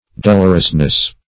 -- Dol"or*ous*ly, adv. -- Dol"or*ous*ness, n.